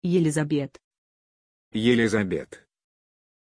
Pronunciation of Elizabeth
pronunciation-elizabeth-ru.mp3